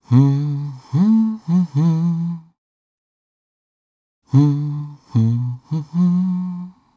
Fluss-Vox_Hum_jp.wav